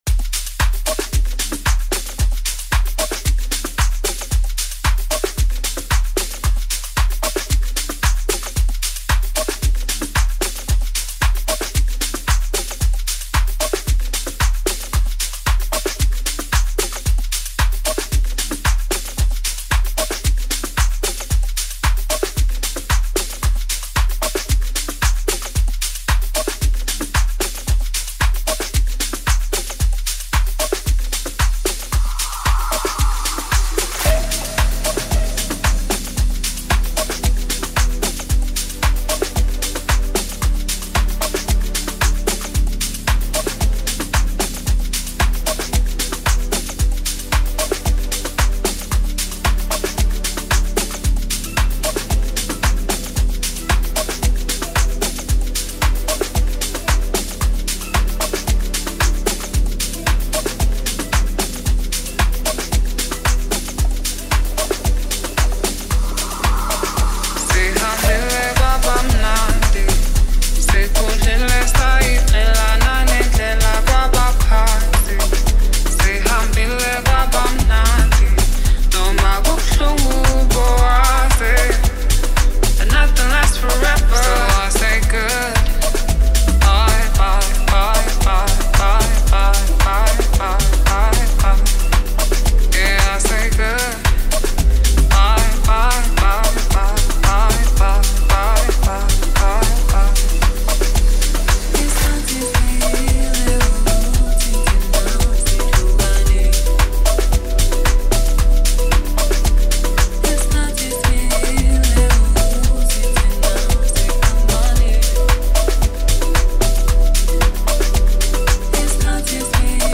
Amapiano Remixes